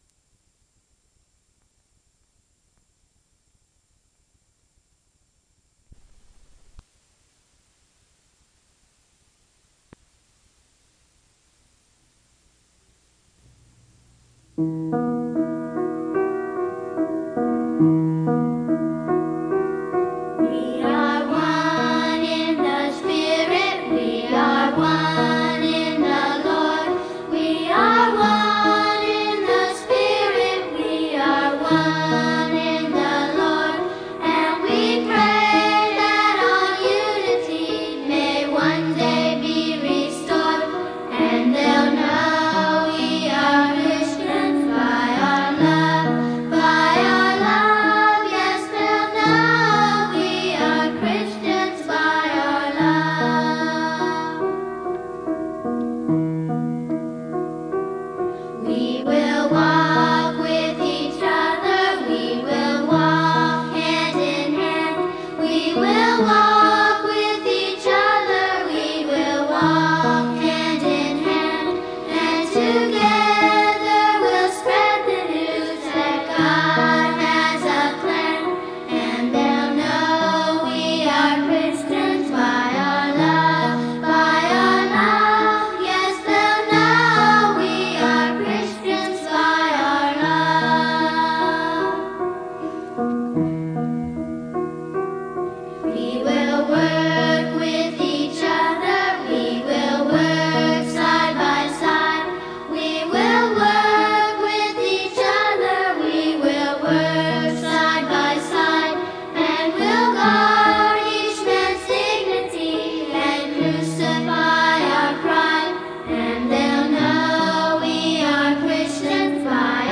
Pass-it-On_Music_Columbus-Bibel-Students_Young-People.mp3